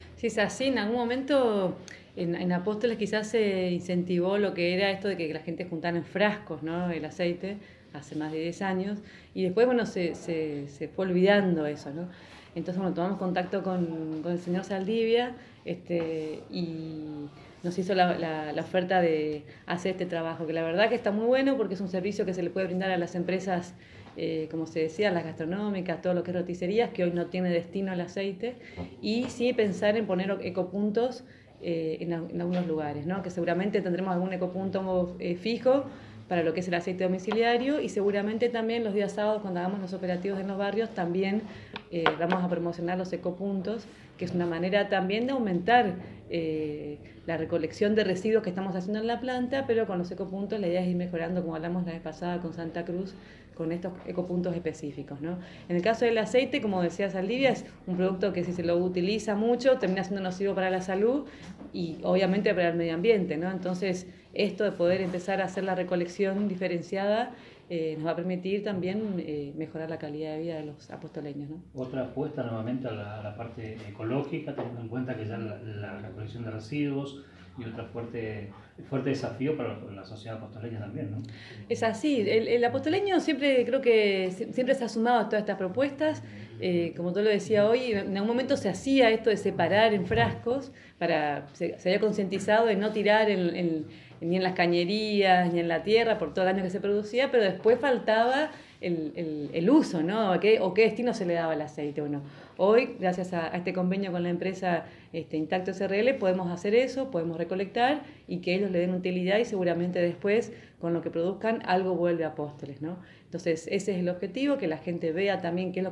Audio: Secretario Medio Ambiente e Industria Fredy Rau
El Secretario de Medio Ambiente e Industria Alfredo «Fredy» Rau realizó la introducción y explicación de la firma del Convenio.